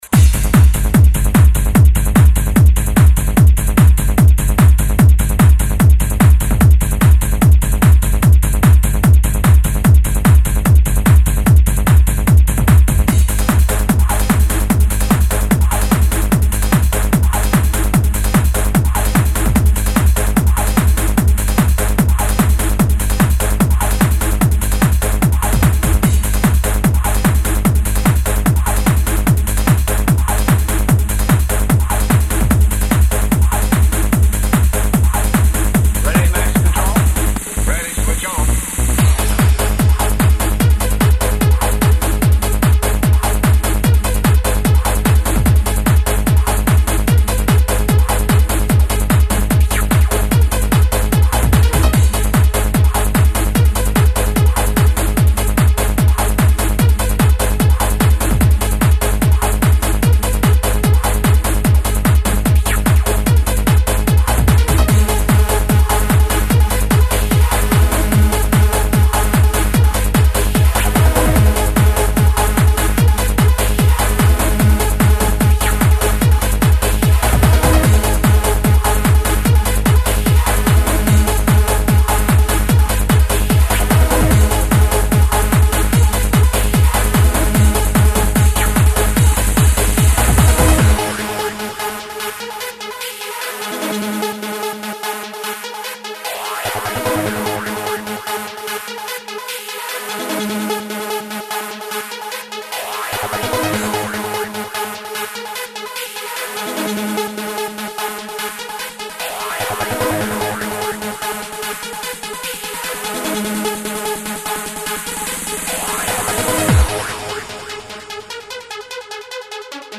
Genre: Club.